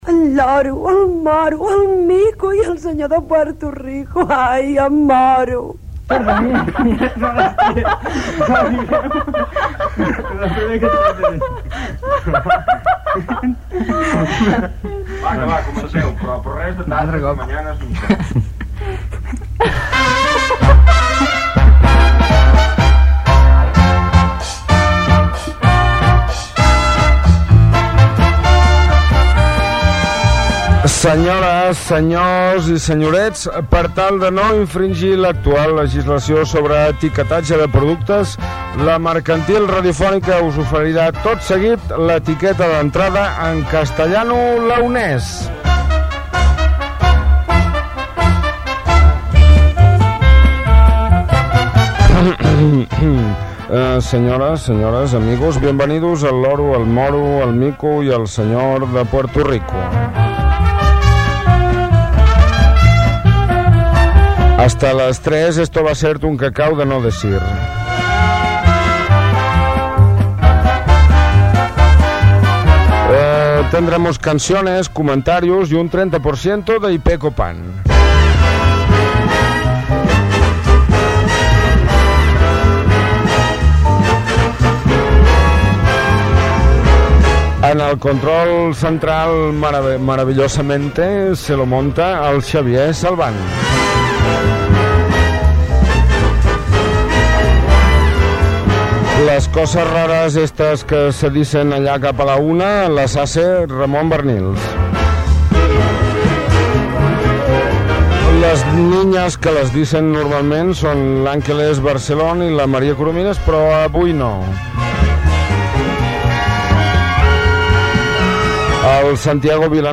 Identificació del programa, presentació feta en "castellano leonés", al·ludint a la normativa vigent de l'etiquetatge de productes.
Entreteniment